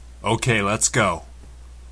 男声OK lets go音效_人物音效音效配乐_免费素材下载_提案神器